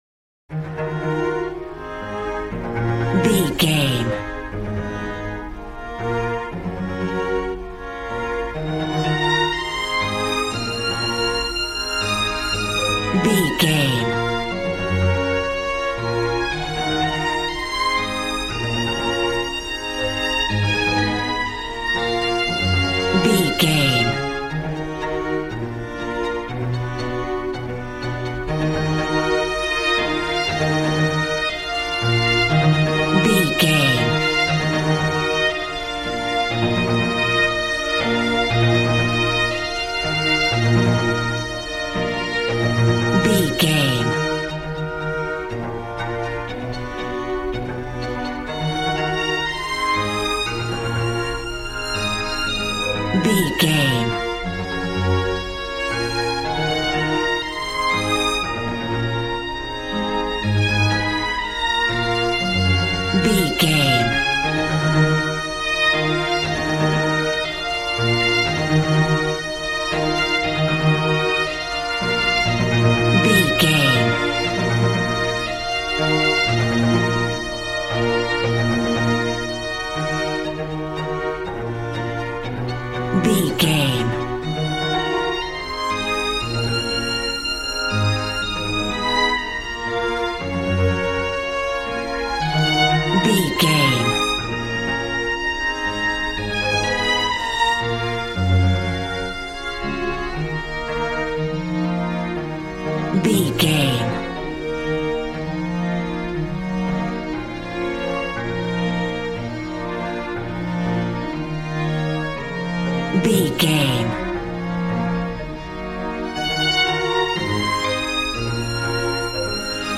Beautiful stunning solo string arrangements.
Regal and romantic, a classy piece of classical music.
Aeolian/Minor
B♭
regal
strings
brass